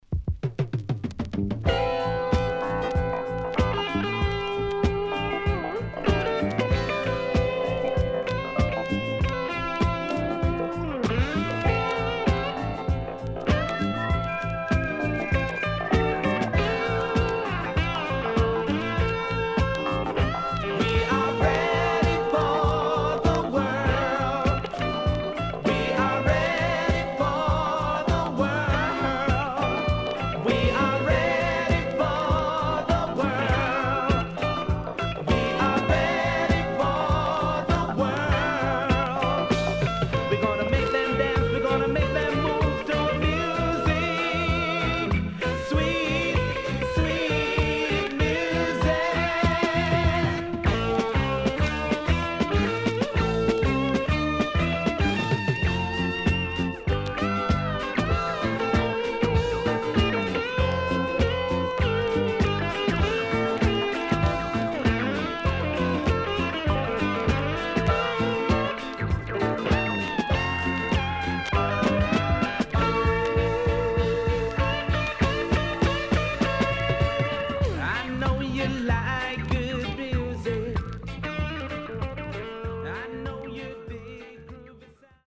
SIDE A:所々チリノイズがあり、少しプチノイズ入ります。
SIDE B:所々チリノイズがあり、少しプチノイズ入ります。